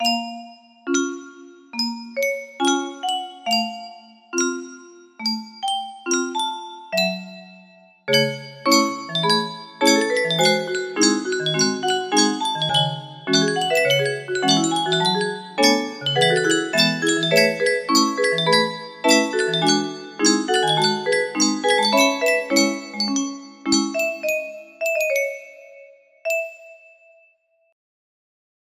Full range 60
intro + outro